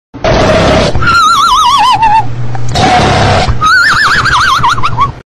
Dog Sleeping Meme Sound Effect Free Download